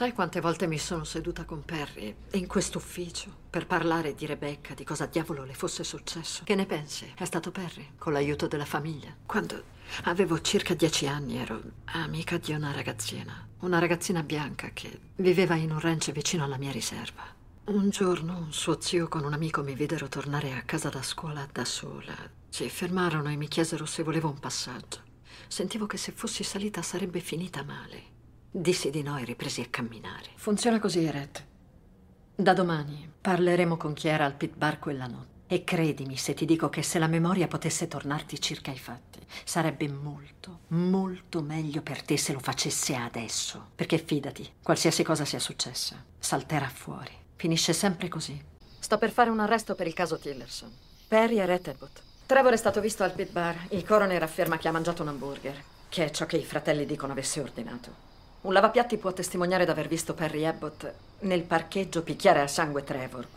nel film d'animazione "Vampire Hunter D - Bloodlust"